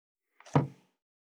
190,ストン,カチ,タン,バタン,スッ,サッ,コン,ペタ,パタ,チョン,コス,カラン,ドン,チャリン,効果音,環境音,BGM,
コップ効果音物を置く
コップ